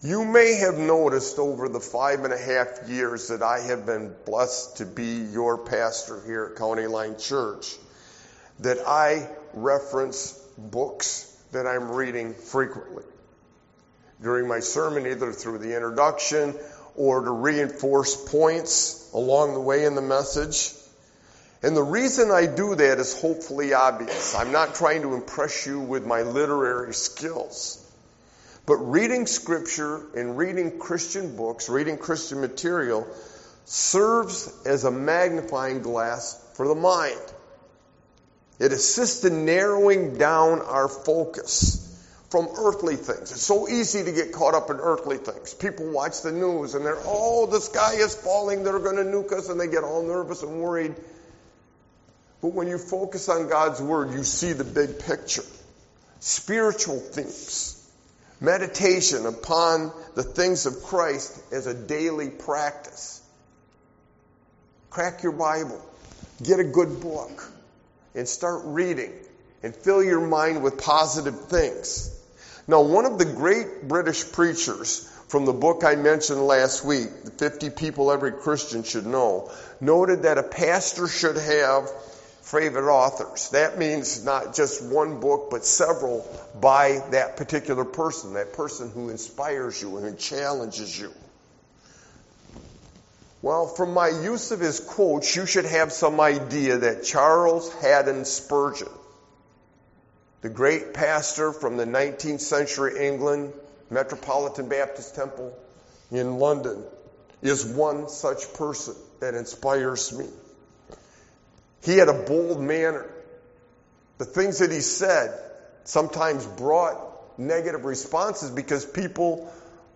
Sermon-Why-a-Sovereign-God-is-crucial-IX-32022.mp3